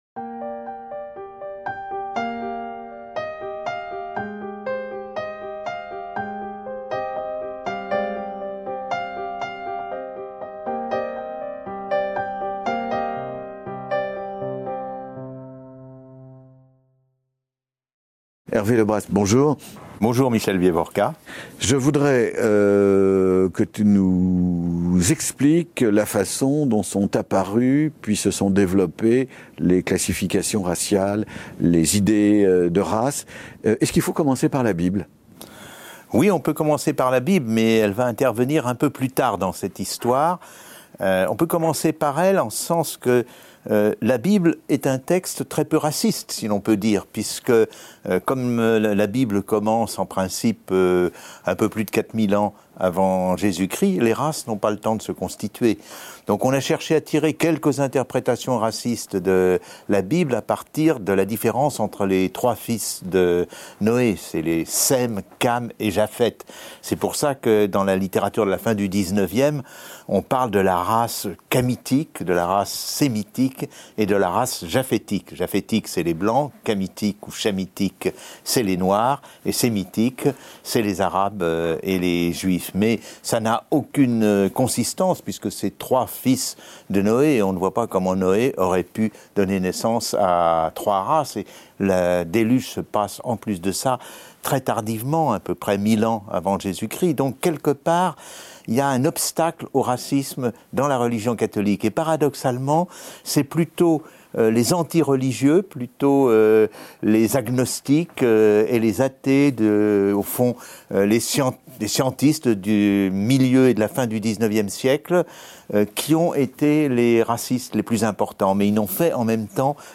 De l’idée de race - Entretien avec Hervé Le Bras | Canal U